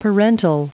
Prononciation du mot parental en anglais (fichier audio)
Prononciation du mot : parental